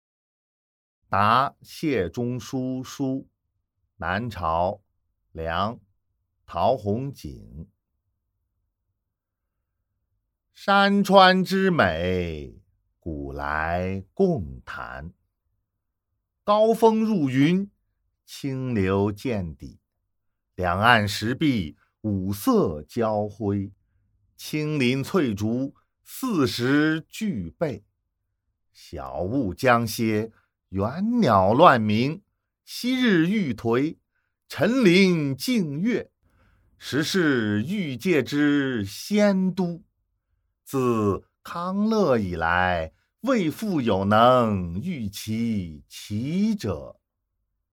［南朝·梁］陶弘景 《答谢中书书》（读诵）